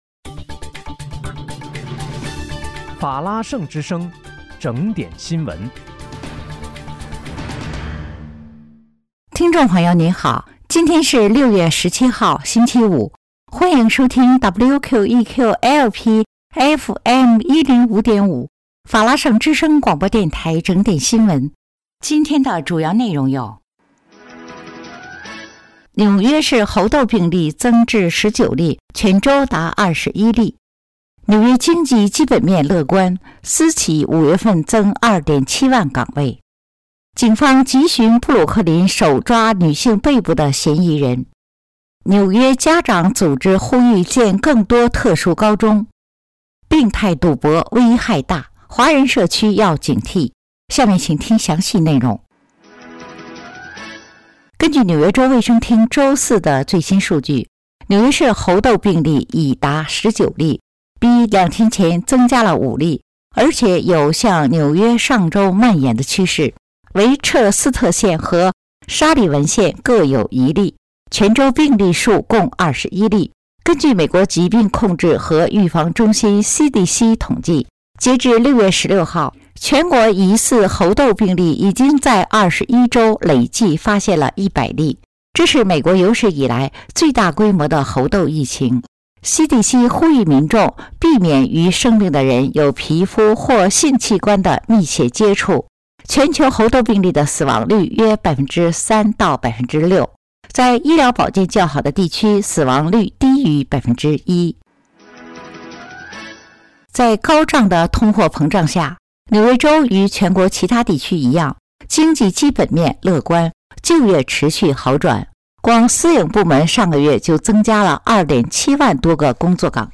6月17日（星期五）纽约整点新闻